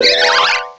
pokeemerald / sound / direct_sound_samples / cries / roserade.aif